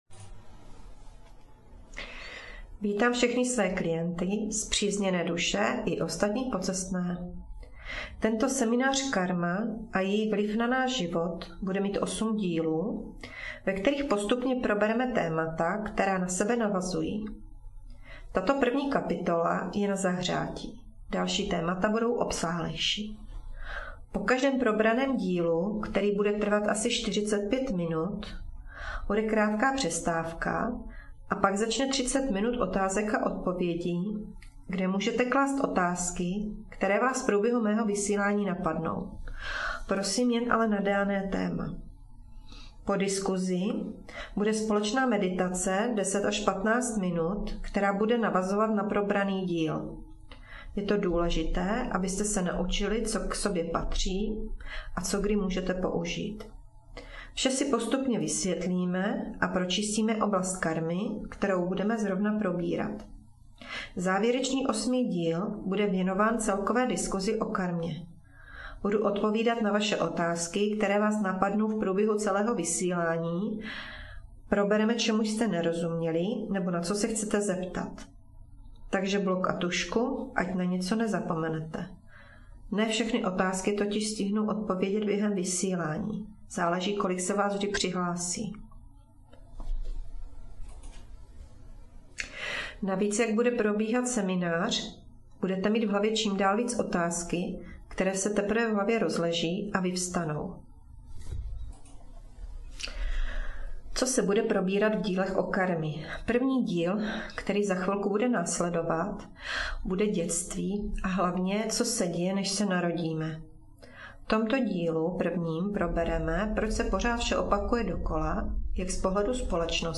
Přednáška Karma 1/8 - Děti a co se děje než se narodíme